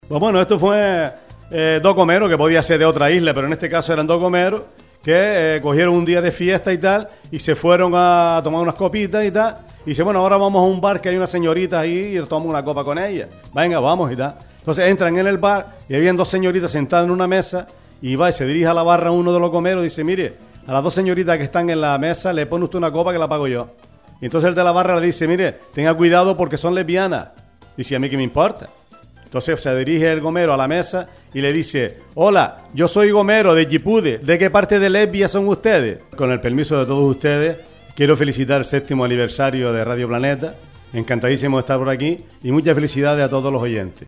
El humorista y televisivo Miguel Sicilia realizo a traves de las ondas Planetarias un curso de risoterapia donde lo pasamos genial